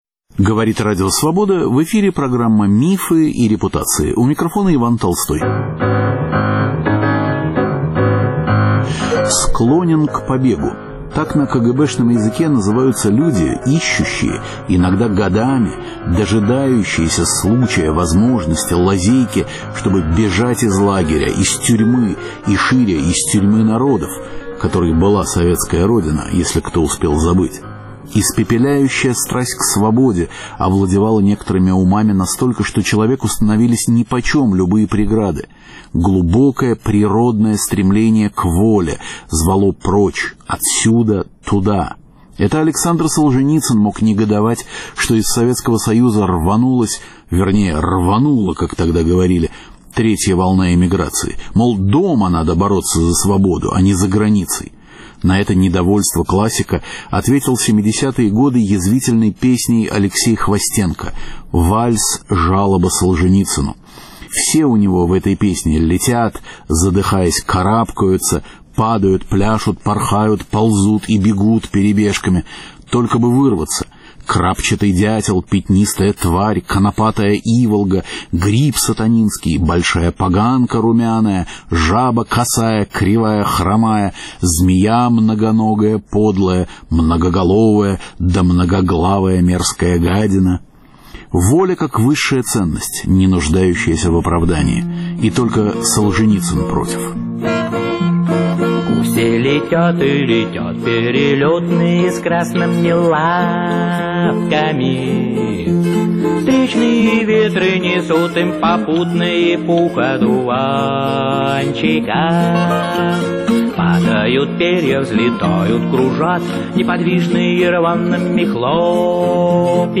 Старые записи из архива Свободы.